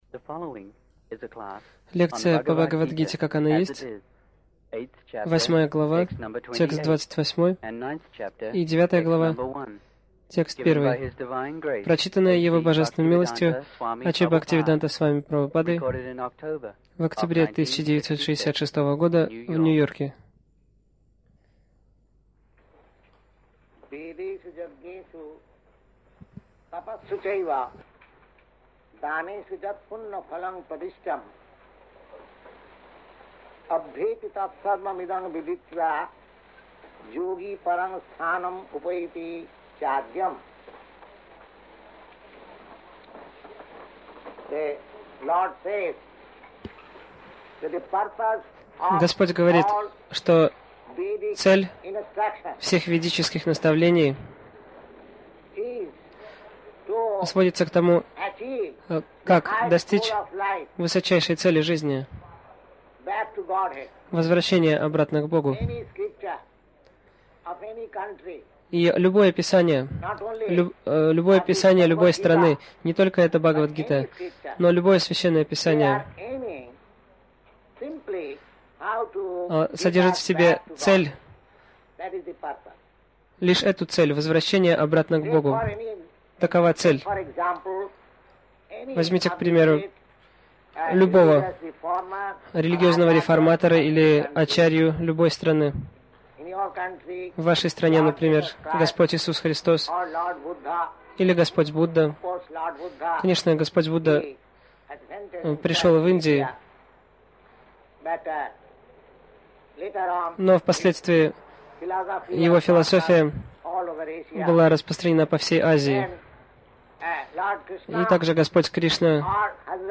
Милость Прабхупады Аудиолекции и книги 21.10.1966 Бхагавад Гита | Нью-Йорк БГ 08.28 + БГ 09.01 Загрузка...